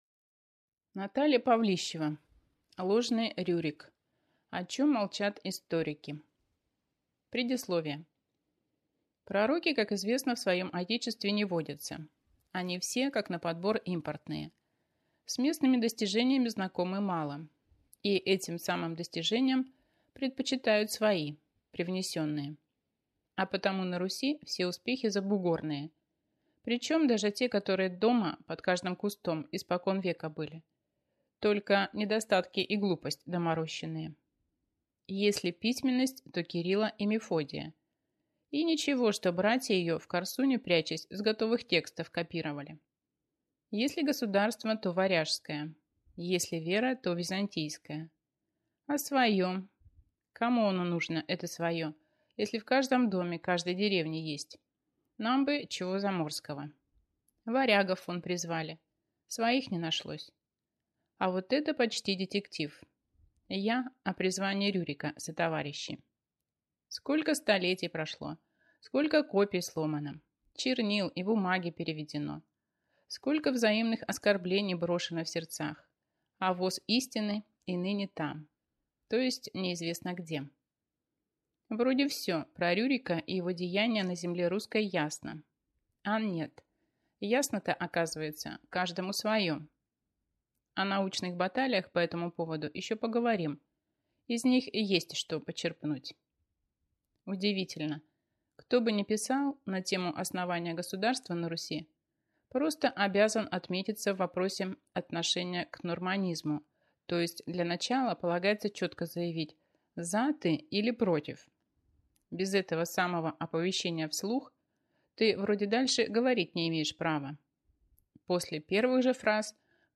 Аудиокнига Ложный Рюрик. О чем молчат историки | Библиотека аудиокниг